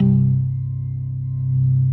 B3LESLIE C 2.wav